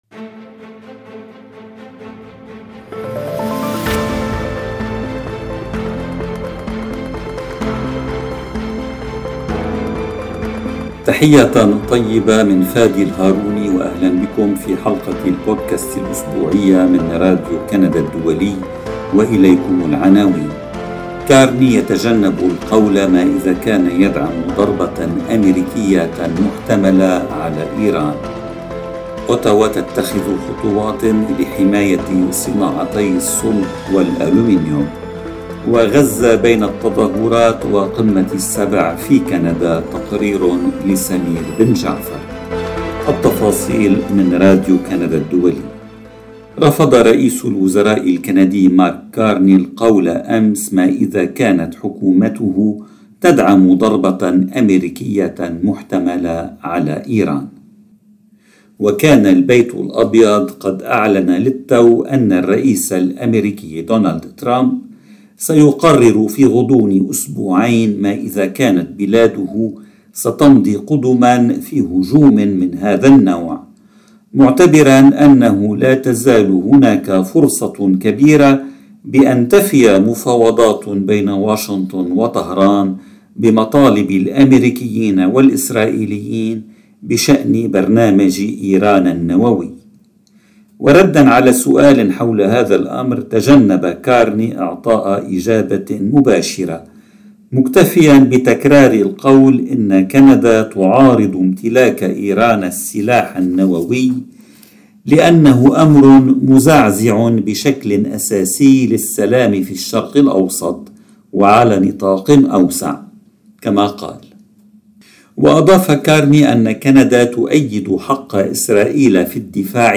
كارني يتجنّب القول ما إذا كان يدعم ضربة أميركية محتملة على إيران. الحكومة الفدرالية تتخذ خطوات لحماية صناعتيْ الصلب والألومنيوم. غزّة بين التظاهرات وقمّة السبع في كندا (تقرير